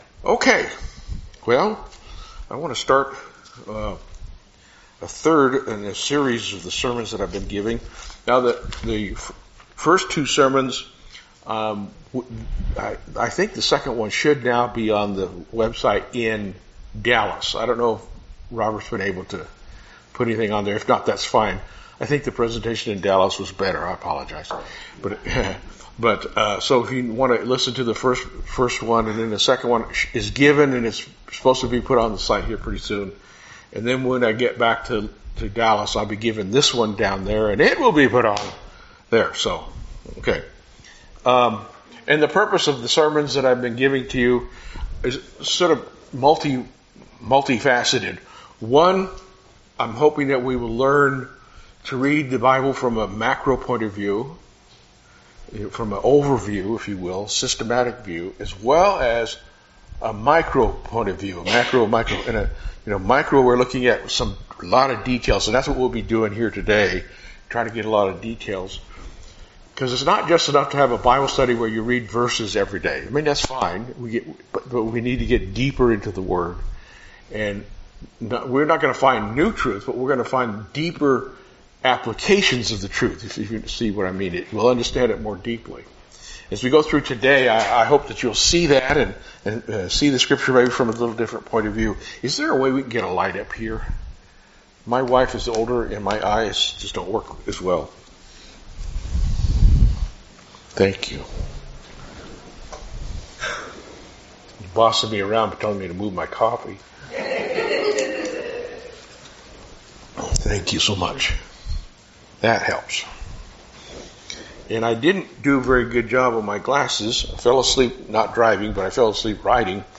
This message is part 3 in a 3 part sermon series.